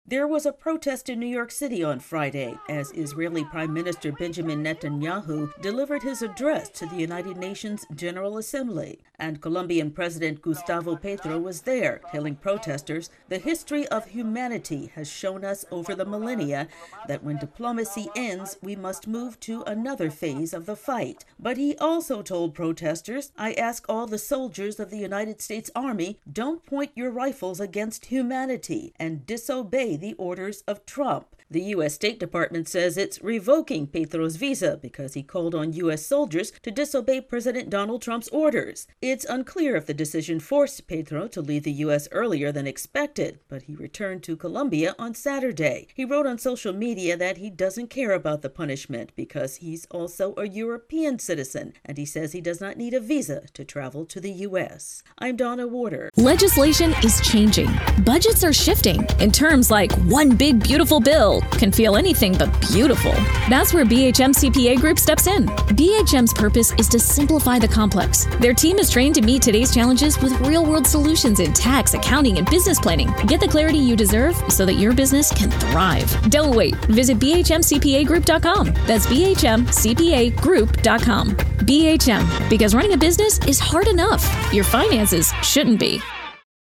The U.S. State Department says it's revoking the visa of Colombia's president, and tensions between the two countries are escalating. AP correspondent